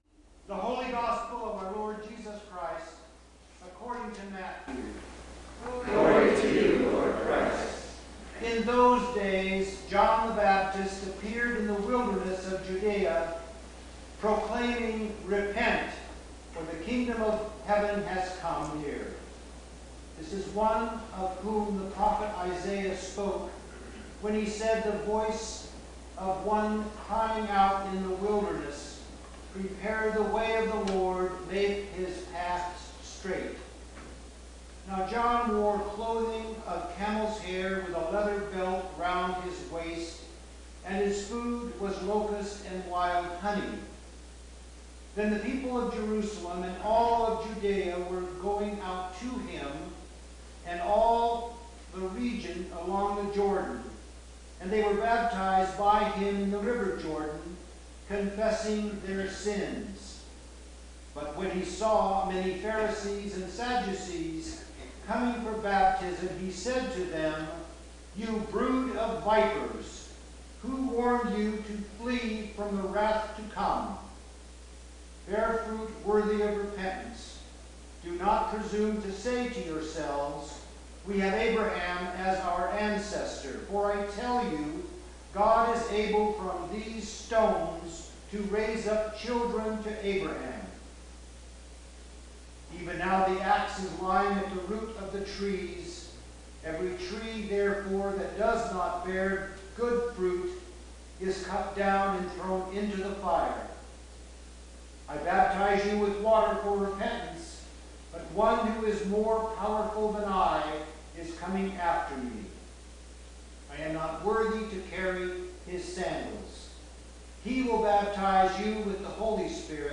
Click below to listen to the sermon by The Rev.